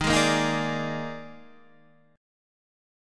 D#M7sus4 chord